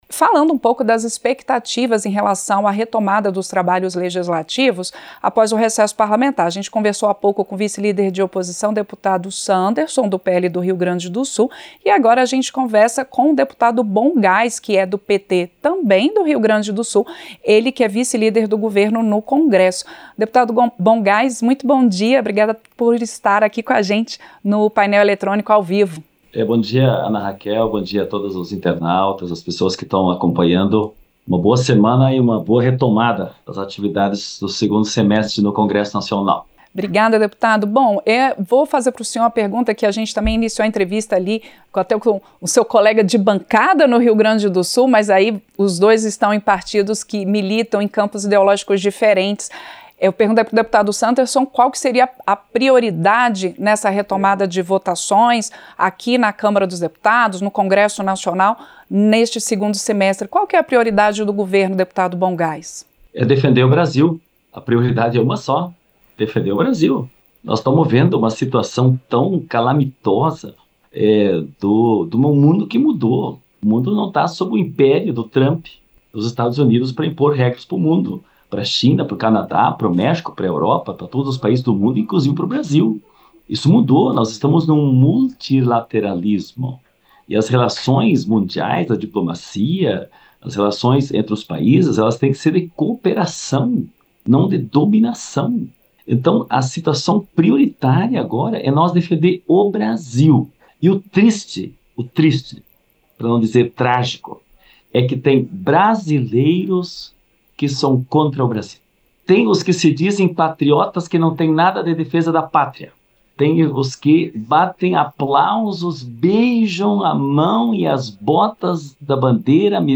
Em entrevista ao Painel Eletrônico, nesta segunda-feira (4), o parlamentar afirmou que Câmara e Senado têm papel fundamental na discussão de uma solução para a crise diplomática provocada pelo tarifaço imposto pelo governo do presidente dos Estados Unidos, Donald Trump, ao Brasil.
Entrevista - Dep. Sanderson (PL-RS)